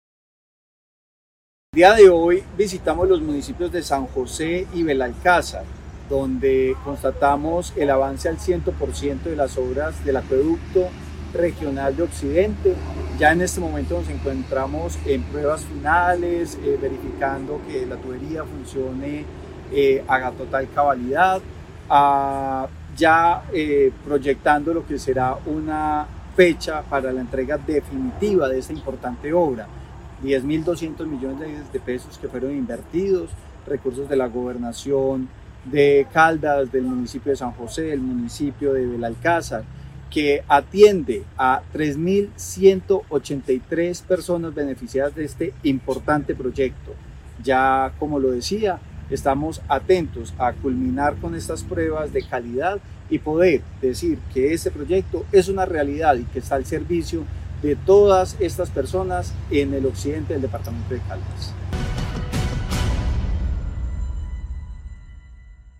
Secretario de Vivienda de Caldas, Francisco Vélez Quiroga.